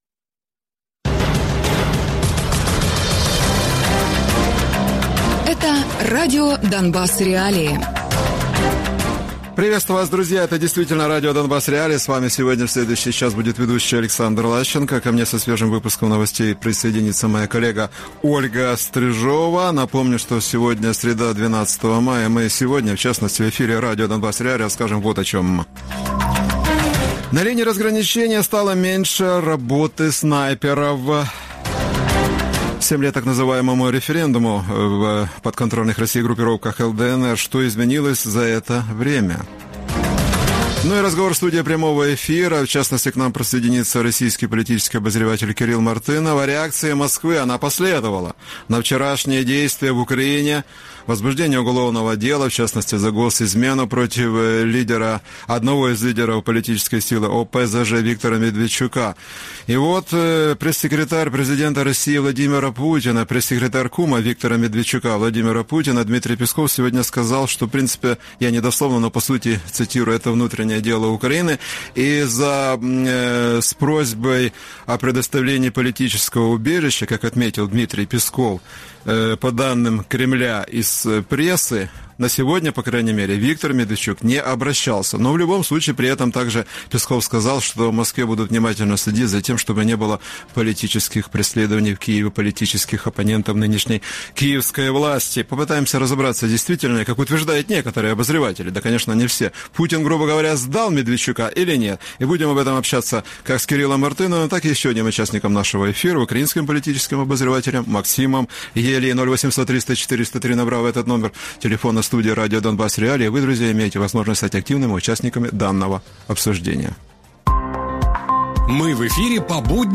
Гості програми радіо Донбас.Реалії